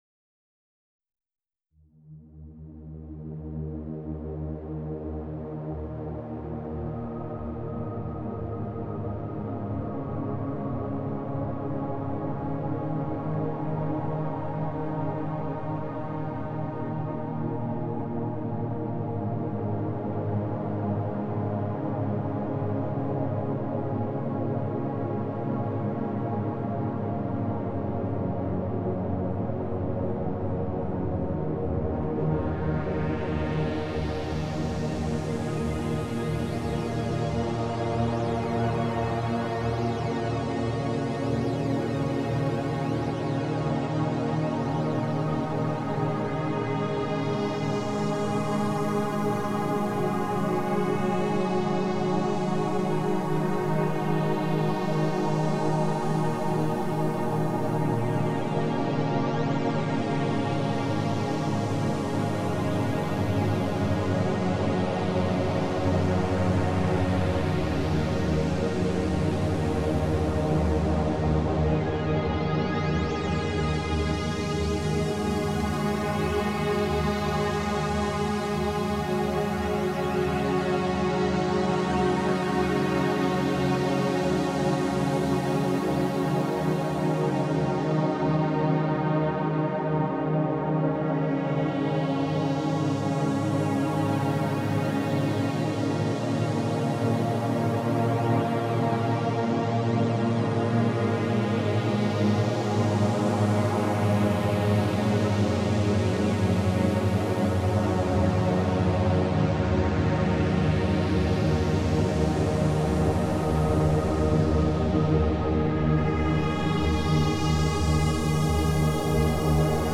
Genre: Berlin School.